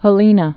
(hə-lēnə)